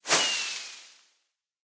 sounds / fireworks / launch1.ogg
launch1.ogg